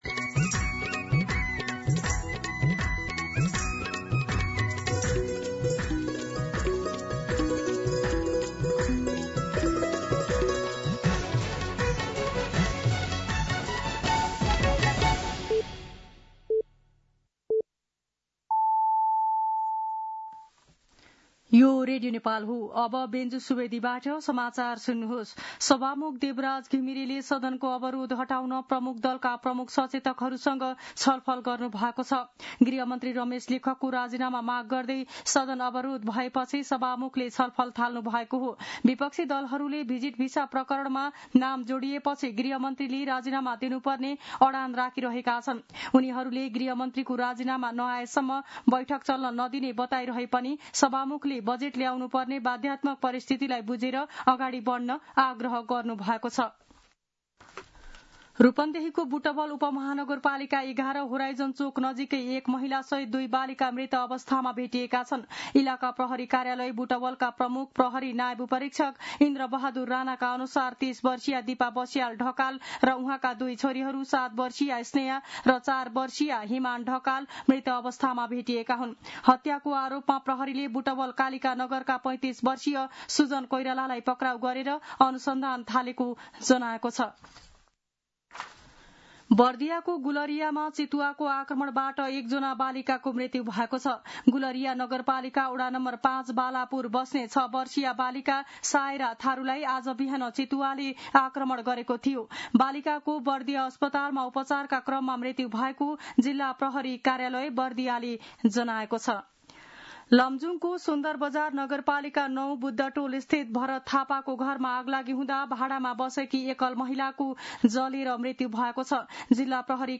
दिउँसो १ बजेको नेपाली समाचार : १४ जेठ , २०८२
1pm-News-14.mp3